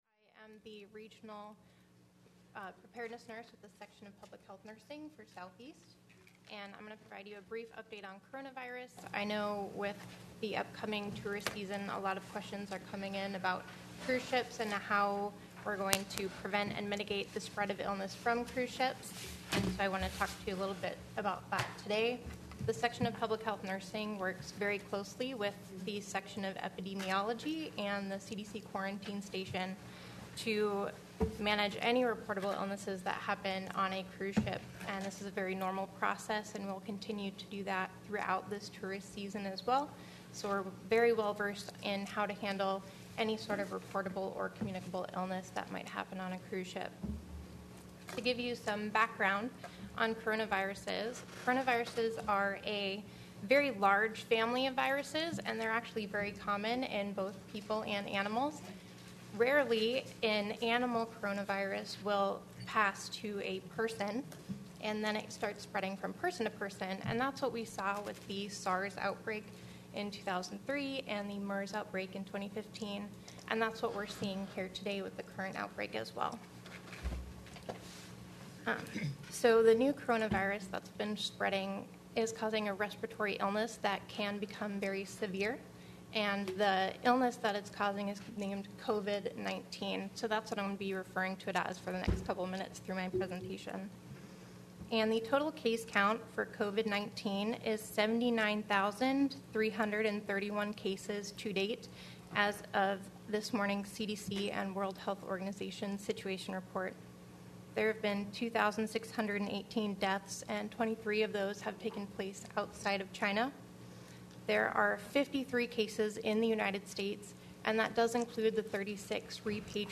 KTOO News broadcasts all regular Juneau City Assembly meetings live.